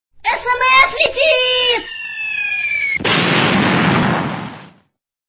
Главная » Рингтоны » SMS рингтоны